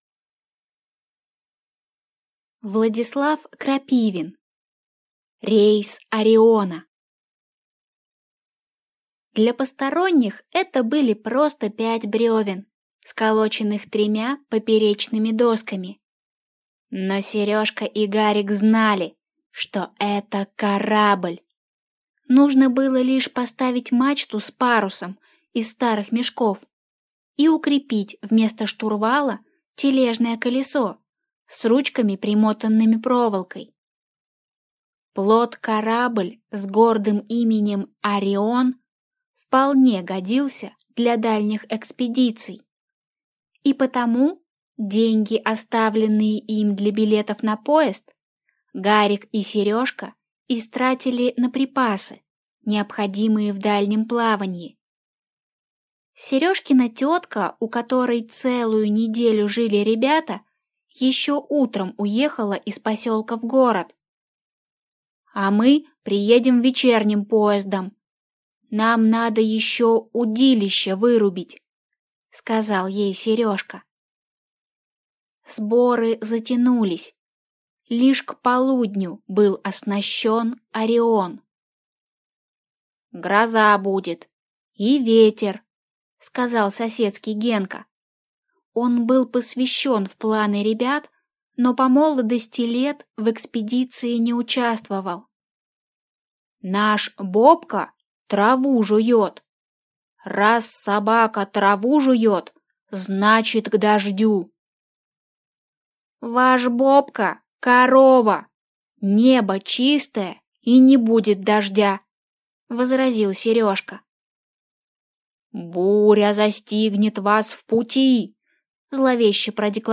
Аудиокнига Рейс «Ориона» | Библиотека аудиокниг